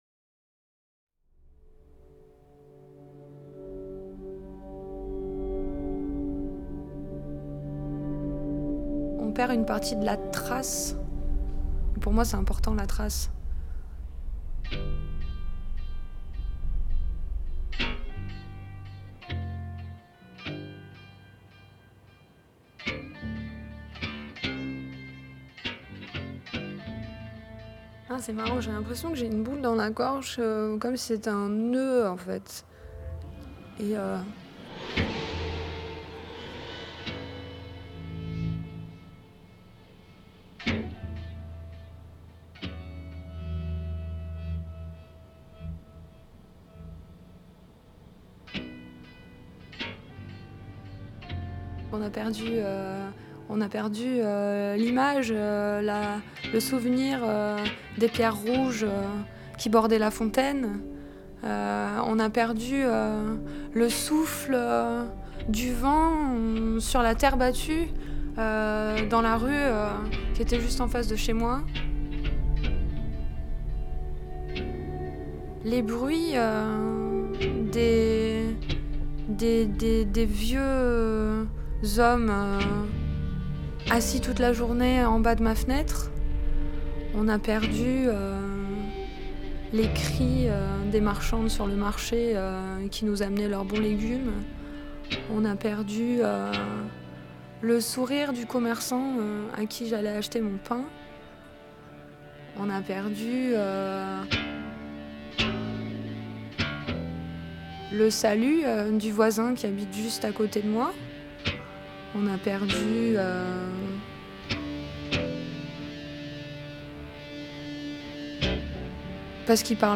Pièce sonore
violon
guitare
chant